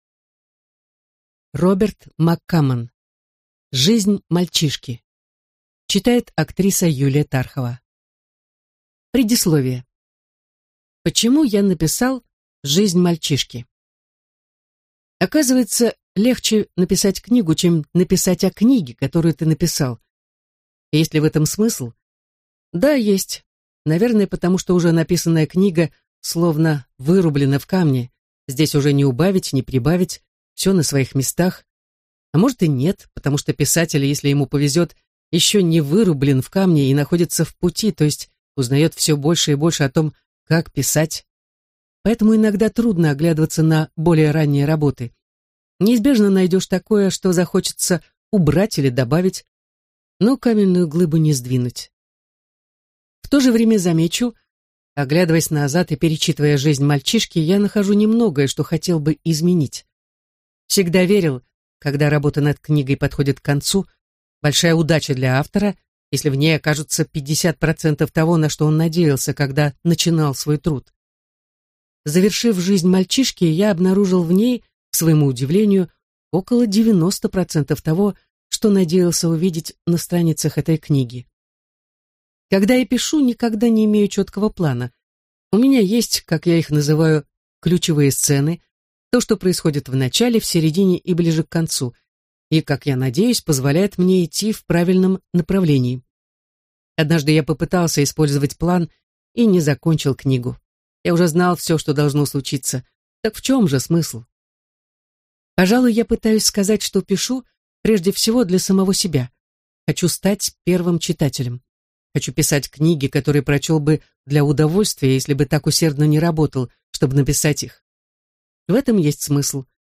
Аудиокнига Жизнь мальчишки | Библиотека аудиокниг